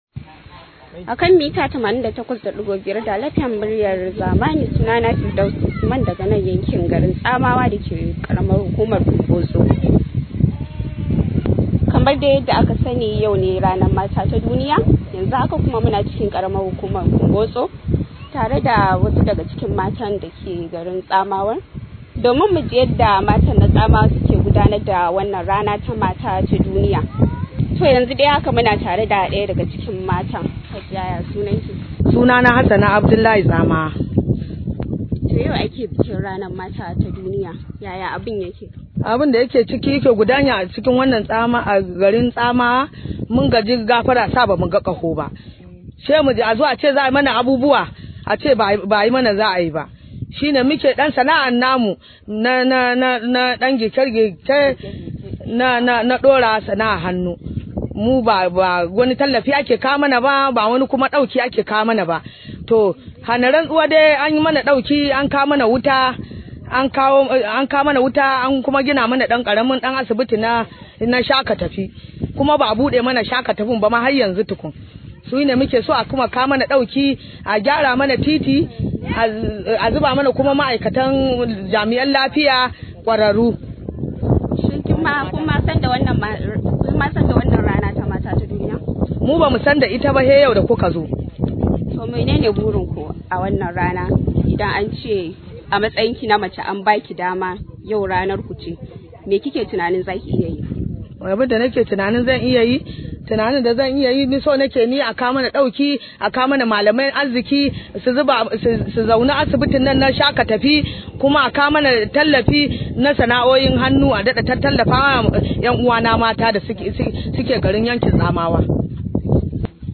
Matan Tsamawa na bayyana ra’ayin su ne a lokacin da su ke ganawa da Dala FM a ranar Mata ta duniya, wanda Majalisar Dinkin Duniya ta ware ranar 8 ga watan Maris na kowace shekara.